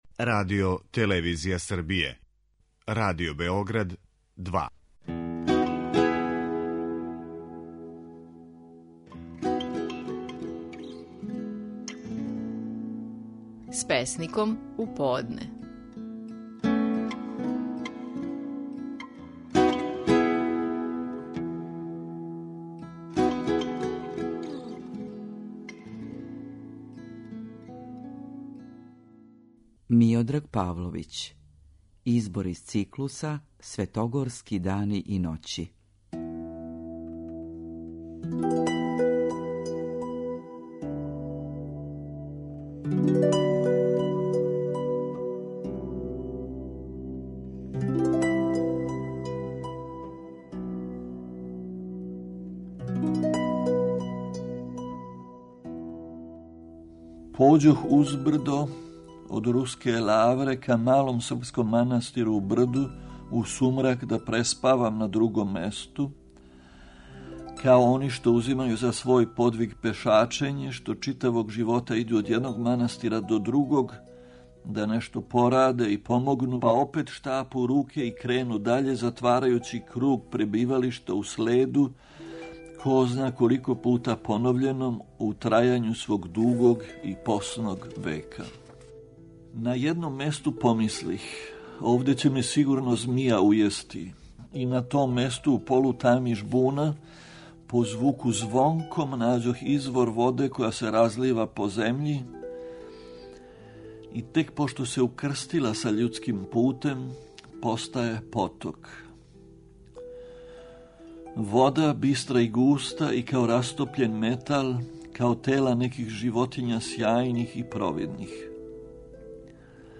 Наши најпознатији песници говоре своје стихове
Слушамо како је Миодраг Павловић говорио стихове песама из циклуса "Светогорски дани и ноћи". Овај циклус, део је збирке "Књига старословна", објављене 1989. године.